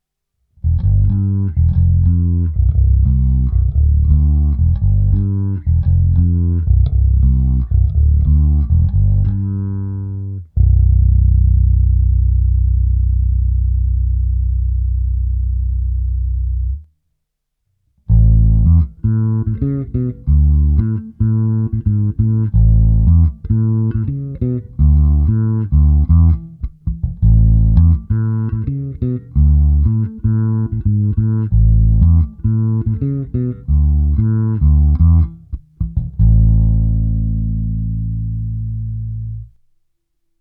Oba snímače